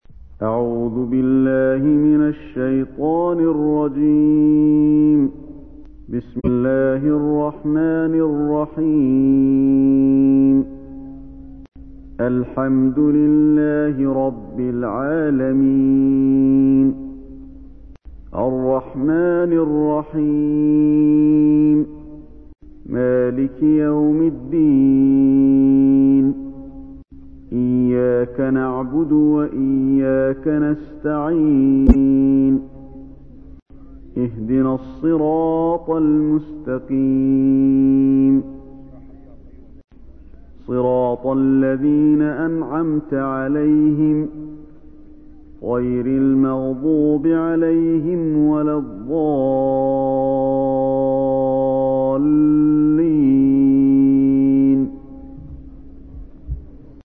سورة الفاتحة / القارئ علي الحذيفي / القرآن الكريم / موقع يا حسين